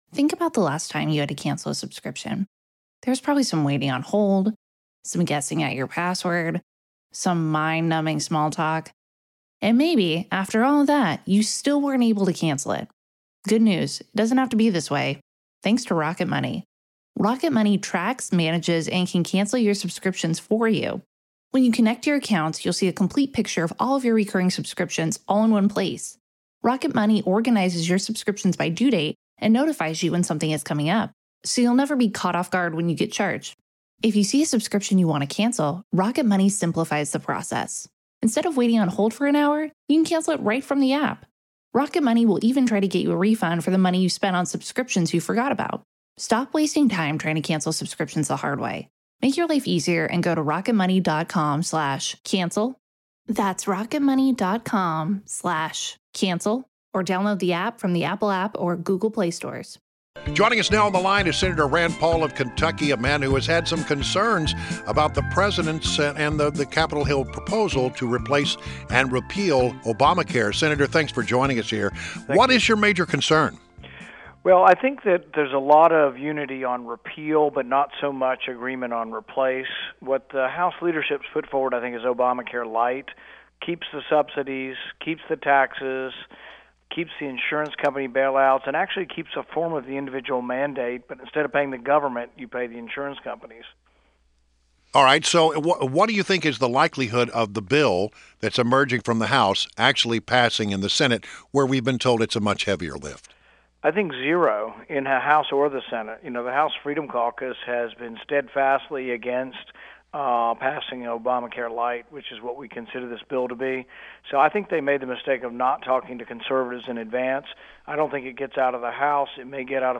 WMAL Interview - SEN. RAND PAUL - 03.10.17